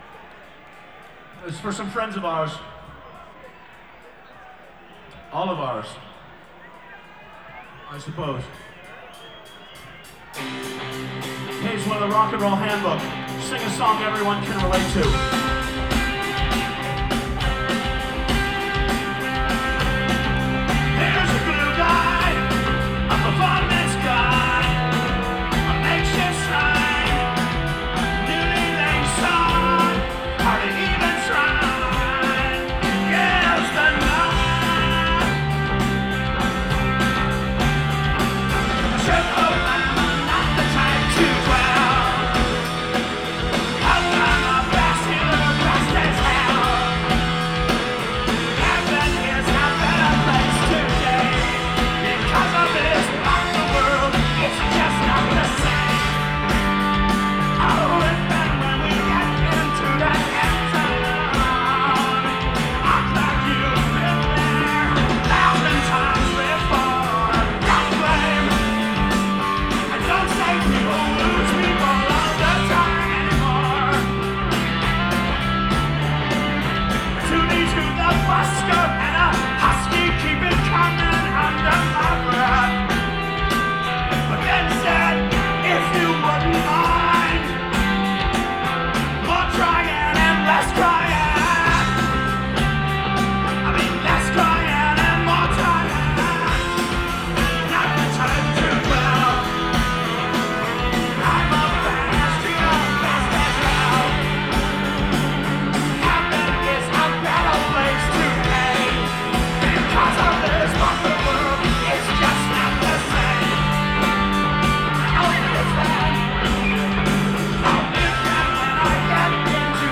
Source: Audience
(Final Live Performance)